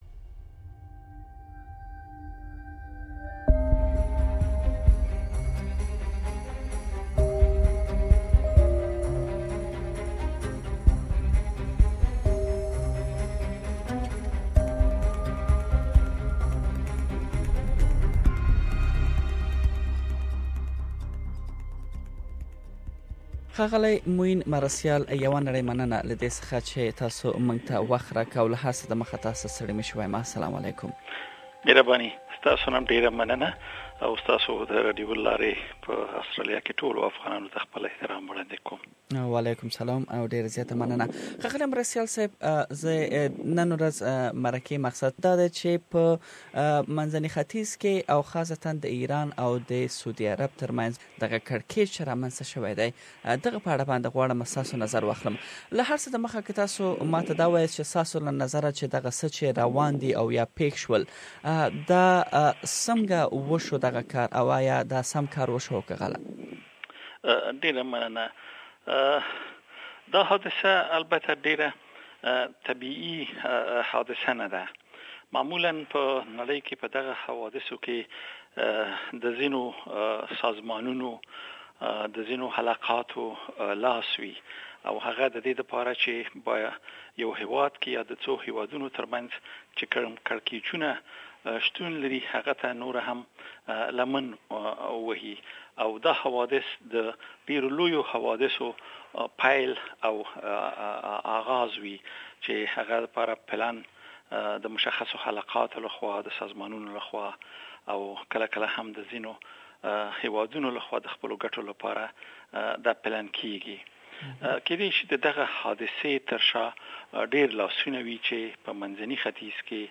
The dispute between Saudi and Iran is getting worse as lots of countries are backing Saudi and they are calling their ambassadors from Iran.The tension between these two powerful countries is as a great threat towards Muslims across the world.Some experts say if the any conflict begins between these two countries the battle field would be Afghanistan and Pakistan. To know more about this dispute we have interviewed former Afghan MP and political analyst Mr Muhammad Moeen Marastial. Please listen to Mr Marastials full interview here.